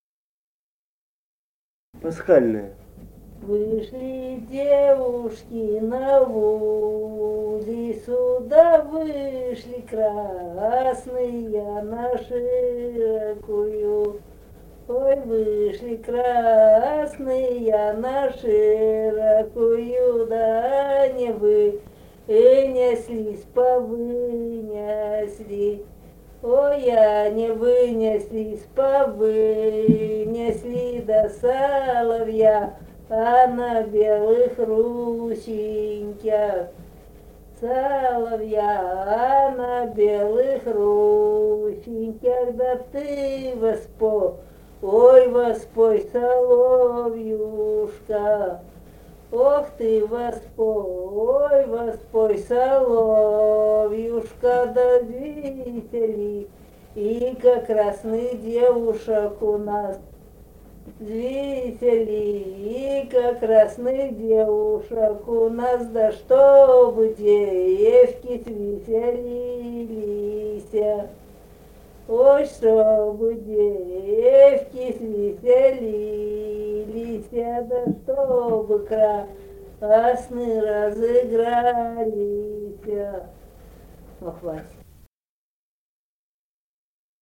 Республика Казахстан, Восточно-Казахстанская обл., Катон-Карагайский р-н, с. Белое, июль 1978.
Прим.: в сборнике 2009 года помещена нотация сольного варианта записи (см.